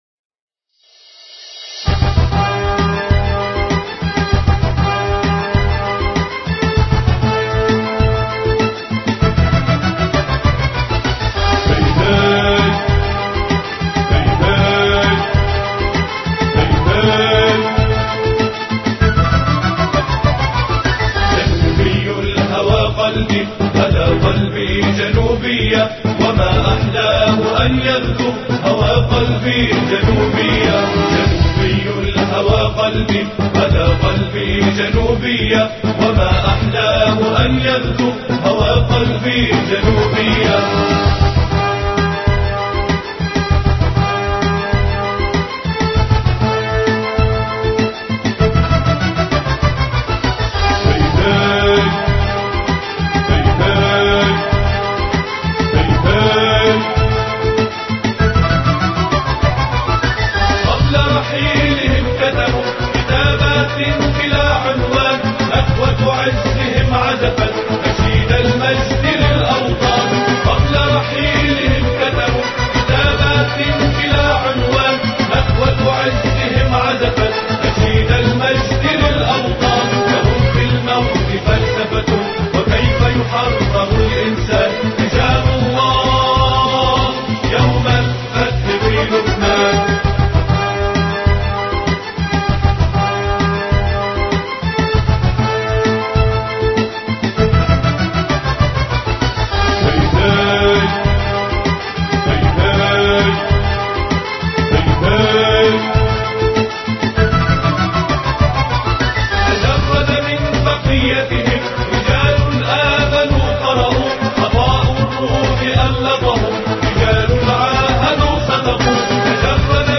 أناشيد لبنانية